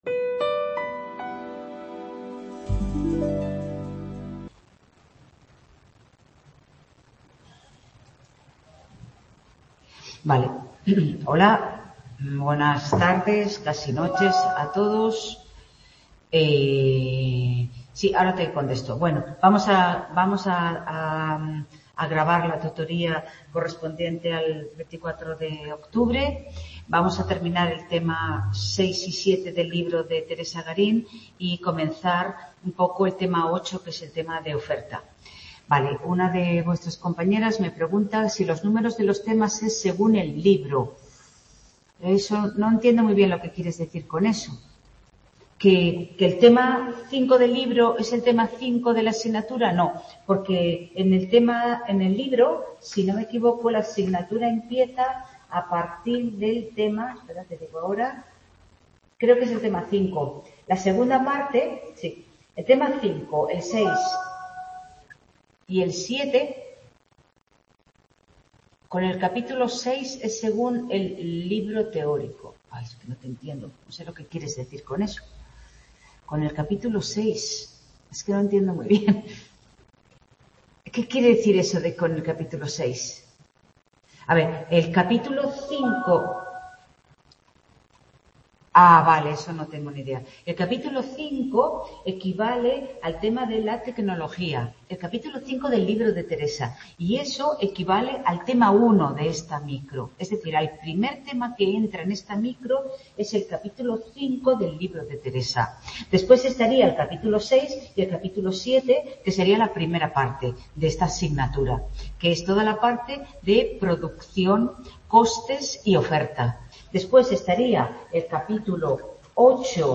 Tutoría Microeconomía 2º ADE, 24 de octubre de 2022 | Repositorio Digital